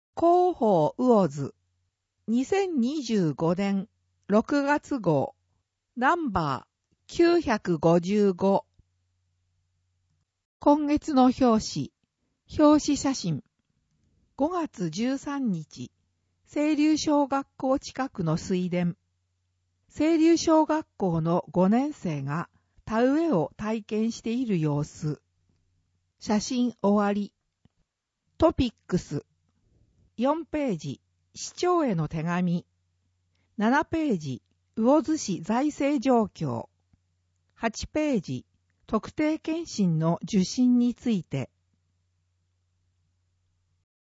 声の広報
魚津市では、音訳サークルうぐいすの会にご協力いただき、視覚障害の方を対象に「広報うおづ」の音訳CDを無料で発送しています。